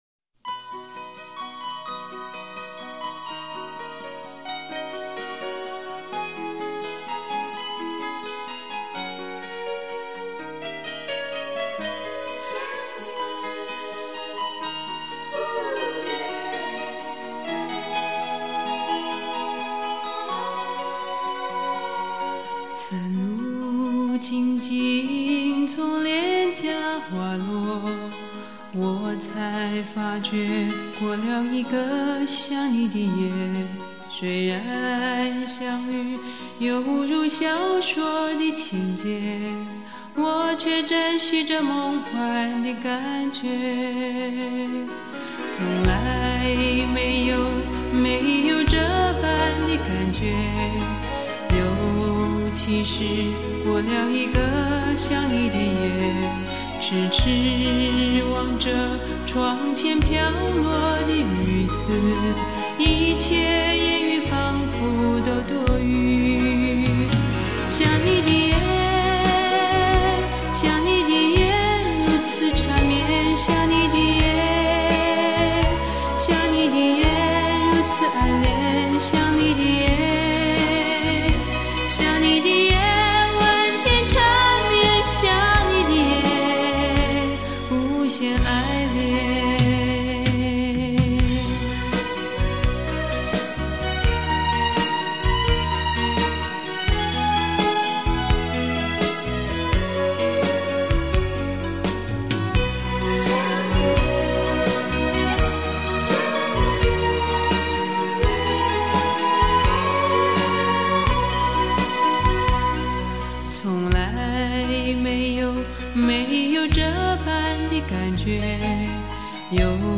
音乐类型：华语流行/女歌手